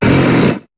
Cri de Groret dans Pokémon HOME.